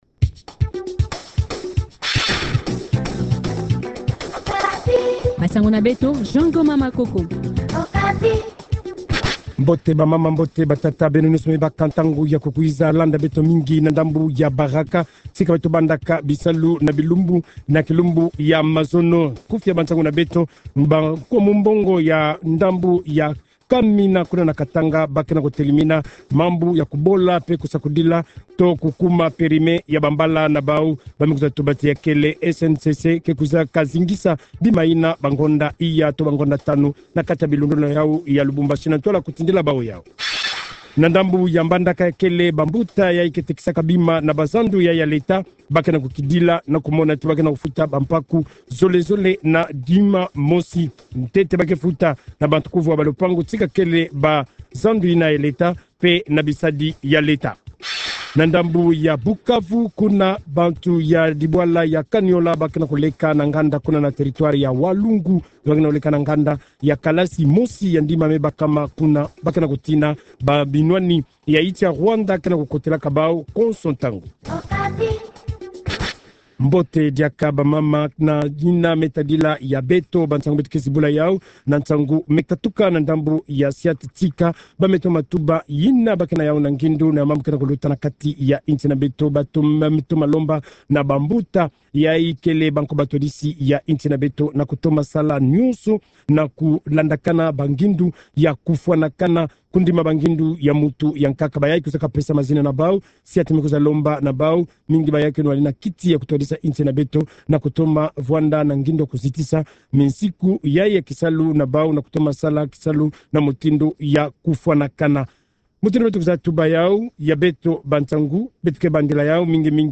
Journal Kikongo